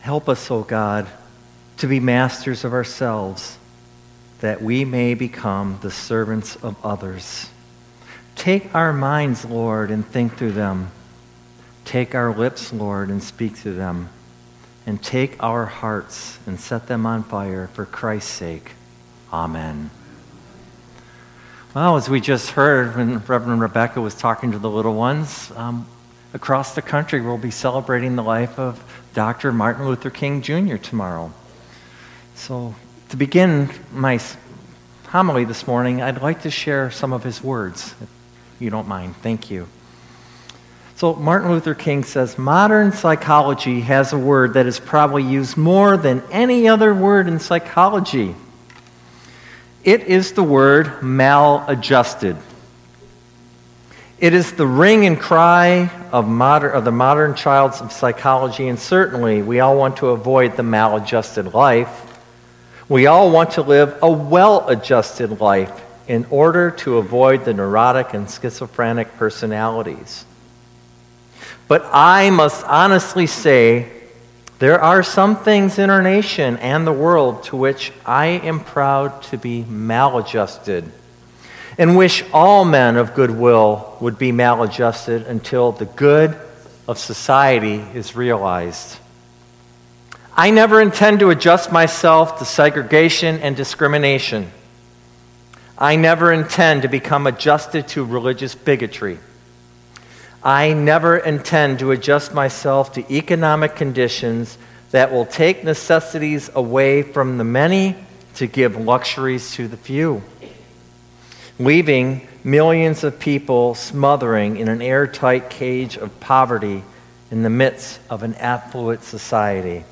Listen to our recorded sermons in high-quality .mp3 format.